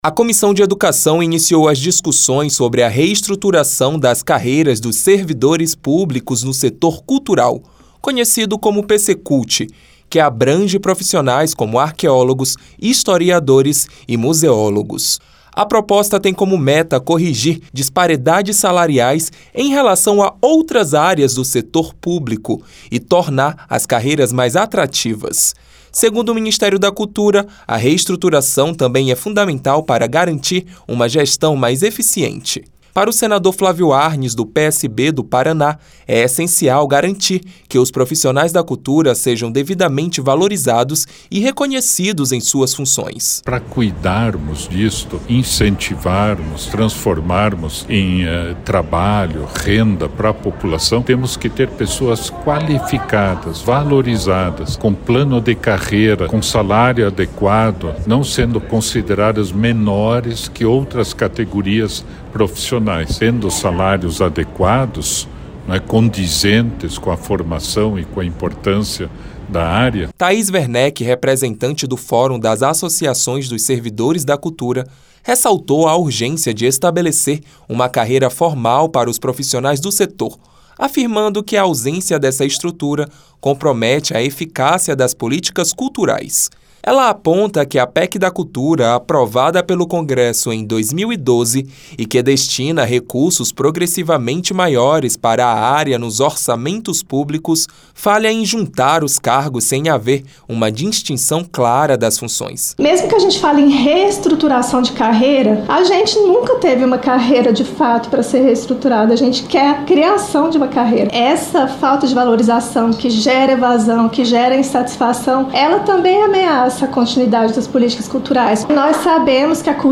A Comissão de Educação e Cultura (CE) realizou na terça-feira (30) audiência pública para discutir a reestruturação das carreiras dos servidores públicos da cultura.
Presidente da CE, o senador Flávio Arns (PSB-PR) destacou a importância de assegurar condições de trabalho justas e a criação de um plano de carreira para esses servidores.